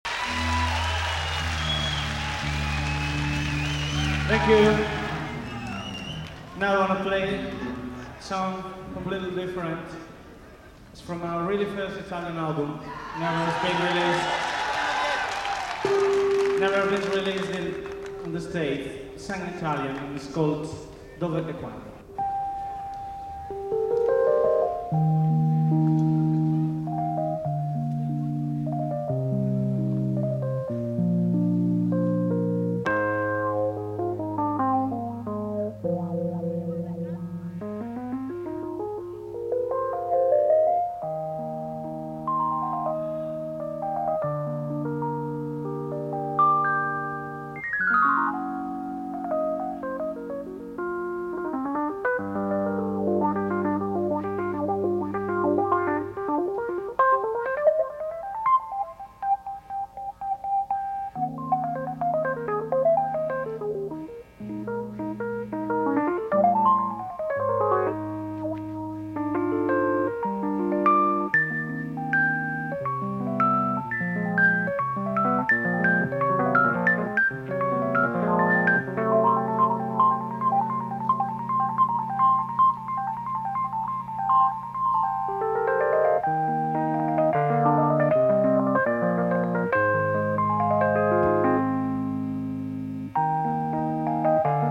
live album